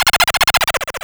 BooLaugh.wav